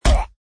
normal zombie die 4.mp3